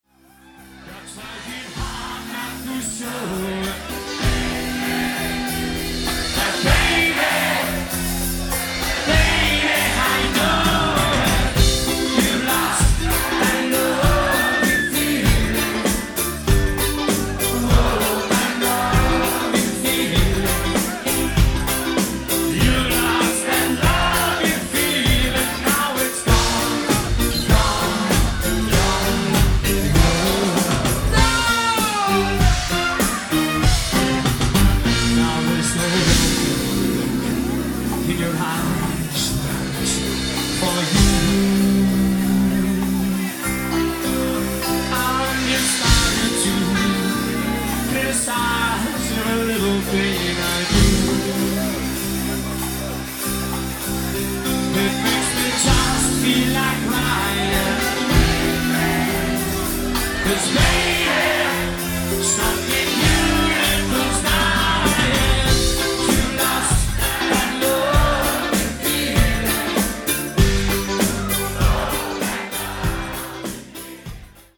Live Demo’s